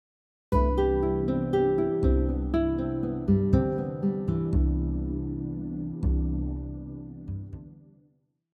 Next, we have a similar example as the previous one except we’re playing a descending four note pattern.